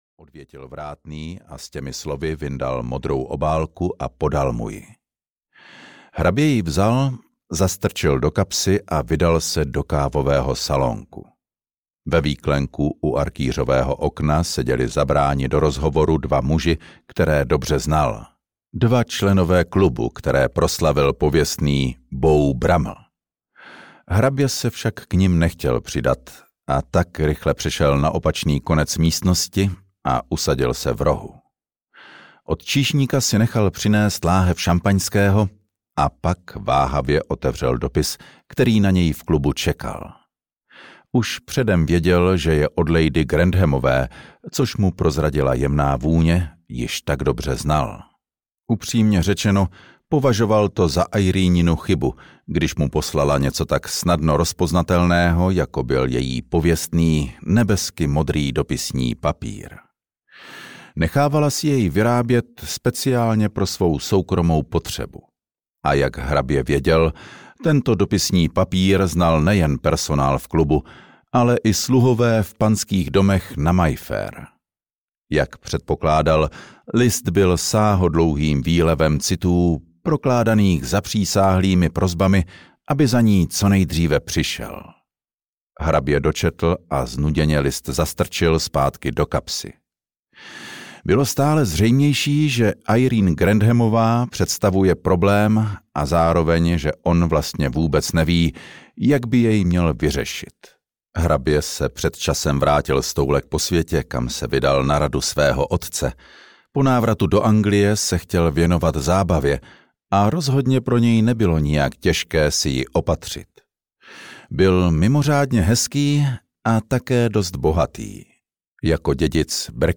Polibek v poušti audiokniha
Ukázka z knihy